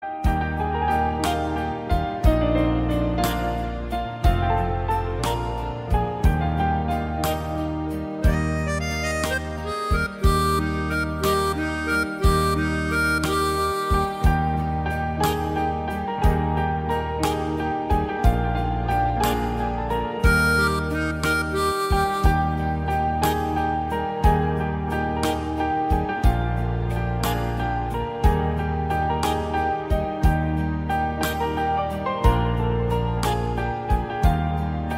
• Category: Old Bollywood Instrumental Ringtone
• Soft and relaxing instrumental music